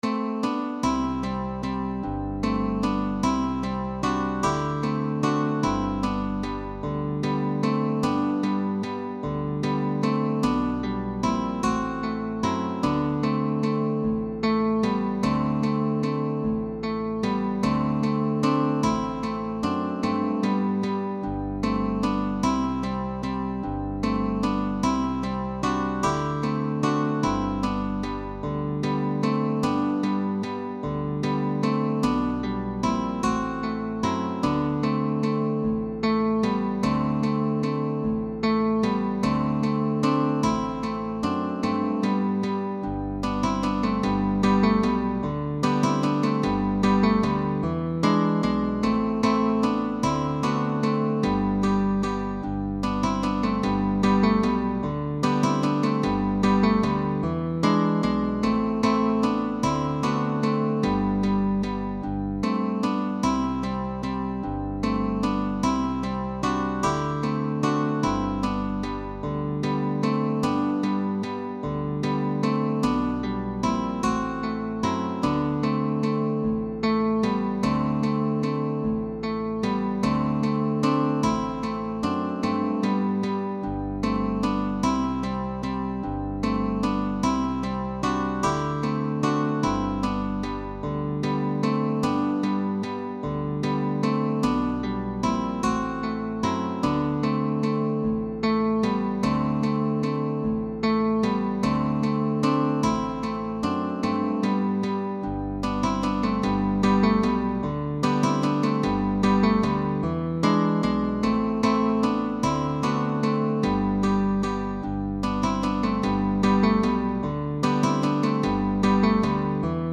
Guitar trio sheetmusic.
arranged by with optional bass.
A folk song from Galicia (Spain) arranged by guitar trio.
GUITAR TRIO